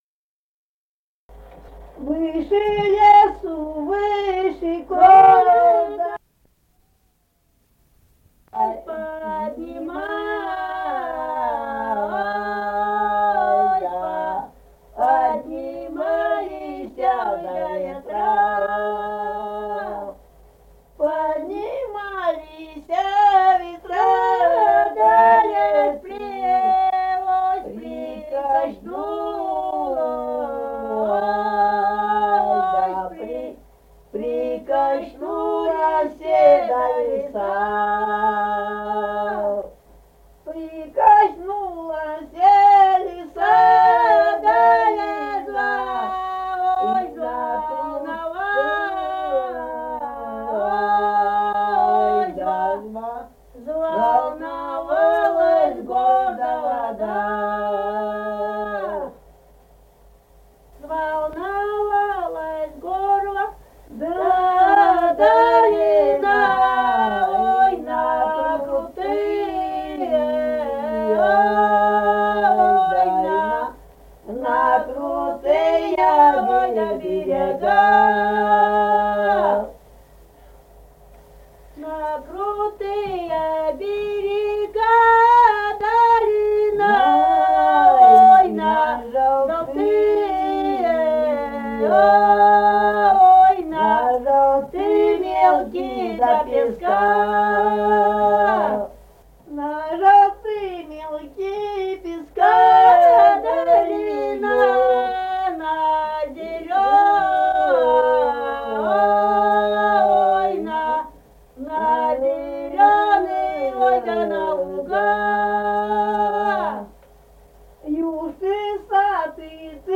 Республика Казахстан, Восточно-Казахстанская обл., Катон-Карагайский р-н, с. Коробиха, июль 1978.
Комм.: пели за столом.